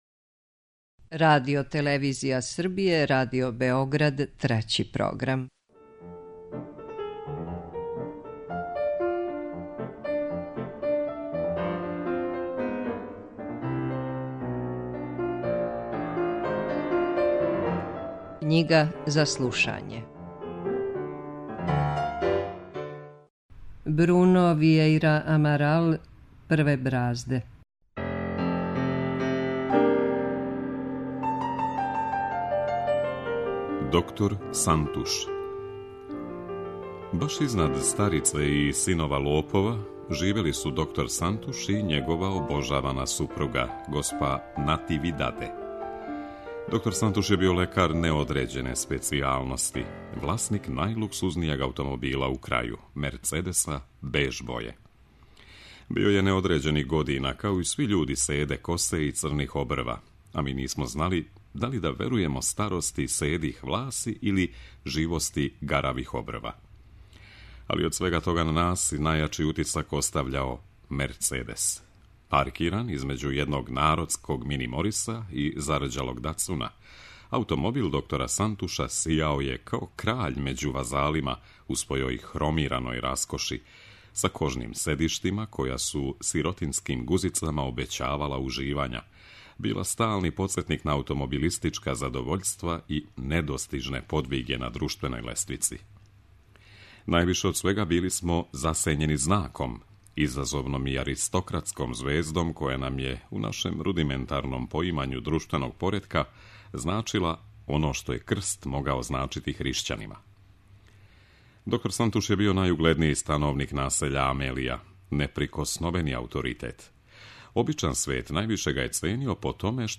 U emisiji KNjIGA ZA SLUŠANjE od 14. avgusta do 10. septembra na talasima Trećeg programa možete pratiti roman „Prve brazde”, čiji je autor portugalski pisac Bruno Viejra Amaral.